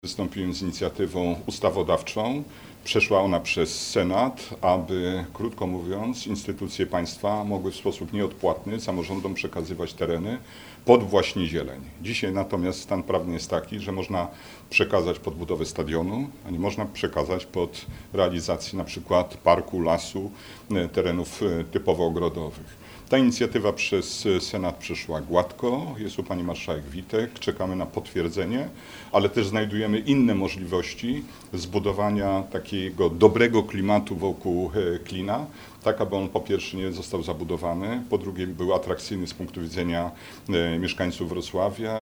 Platforma Obywatelska zorganizowała konferencję w sprawie poparcia Zielonego Klina Południa Wrocławia.
– Chcę bardzo mocno wesprzeć projekt Zielonego Klina Południa Wrocławia – dodał Senator RP.